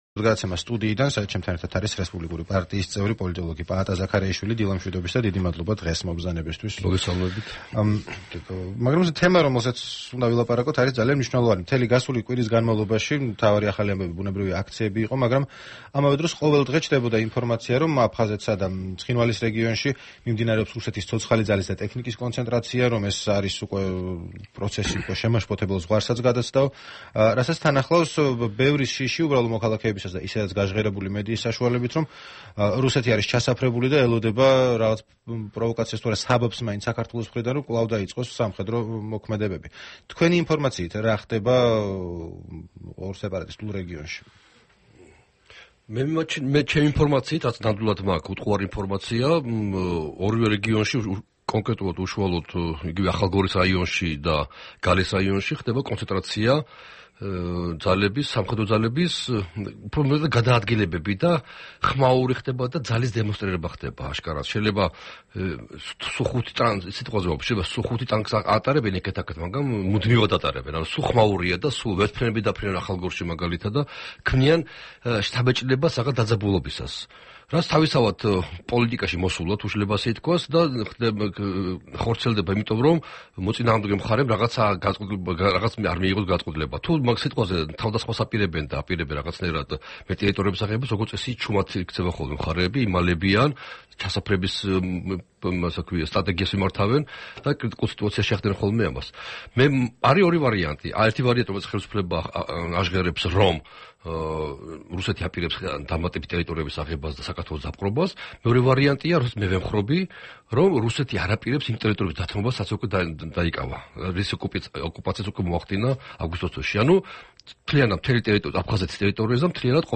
ორშაბათს რადიო თავისუფლების დილის პროგრამის სტუმარი იყო პოლიტოლოგი, რესპუბლიკური პარტიის წევრი პაატა ზაქარეიშვილი, რომელმაც აფხაზეთსა და ცხინვალის რეგიონში შექმნილ მდგომარეობაზე ისაუბრა.